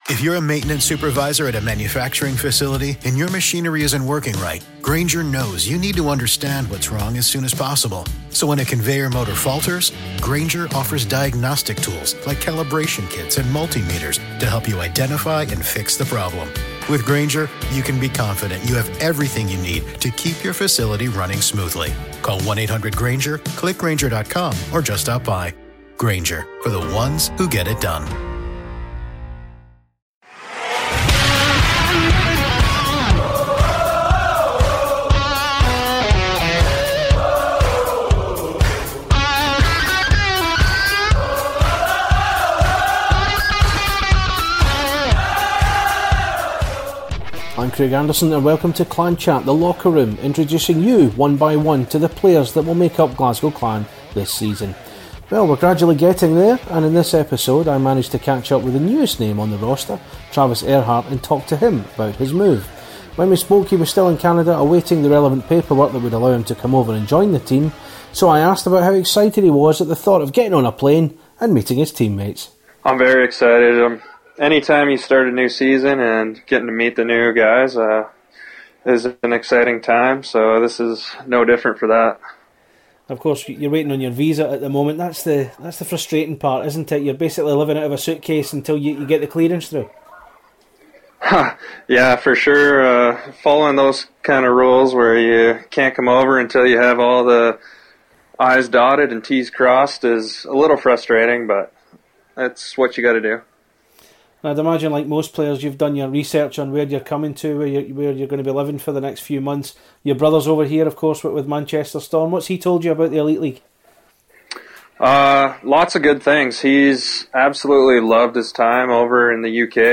but the ninth player to feature in our series of interviews with the Glasgow Clan roster for 2018/19.